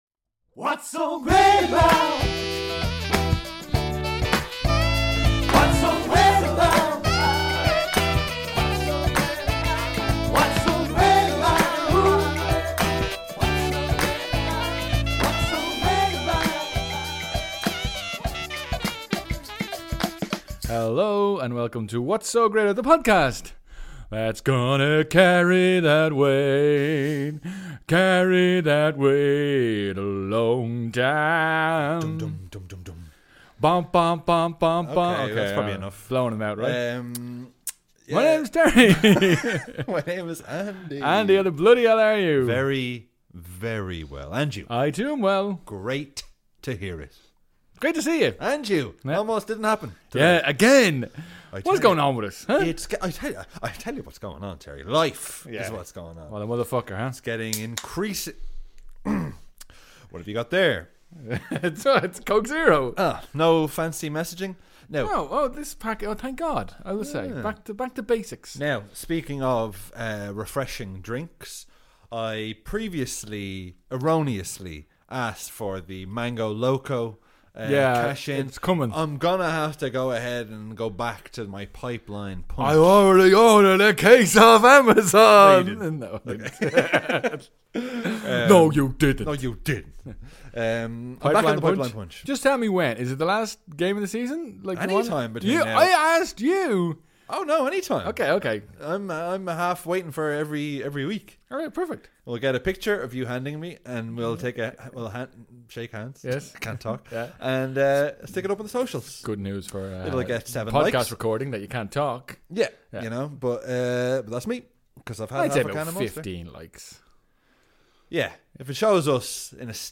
Its a patented SOLO PODCAST.